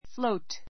flóut ふ ろ ウ ト